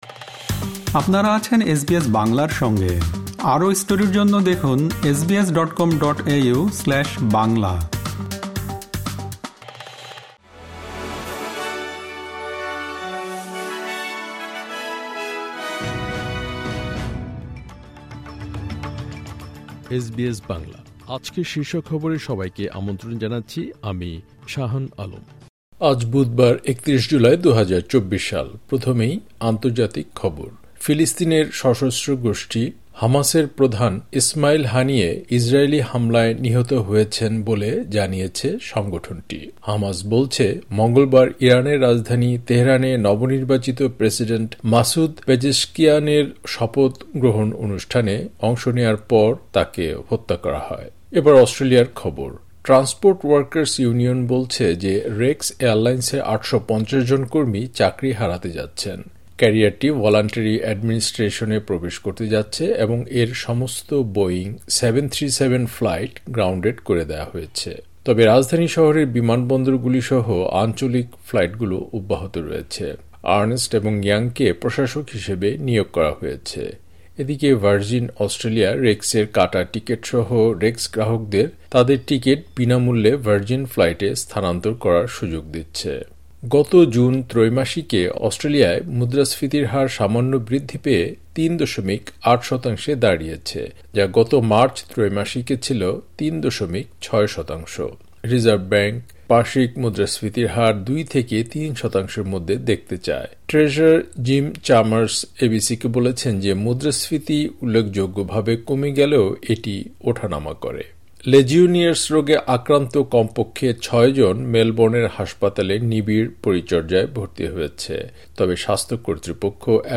এসবিএস বাংলা শীর্ষ খবর: ৩১ জুলাই, ২০২৪